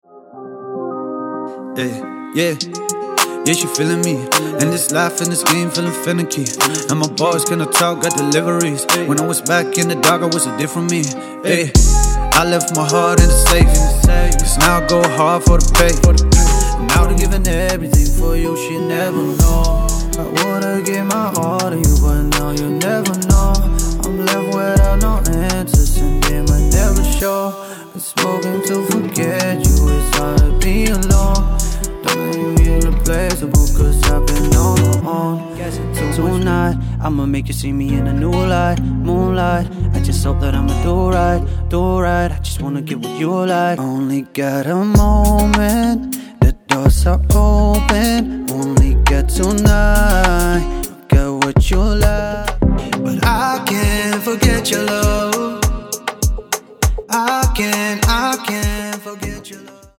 • Hip hop
• Afropop
• R'n'b
chill, melodisk RnB-rap med tunge 808s og melankolske vibes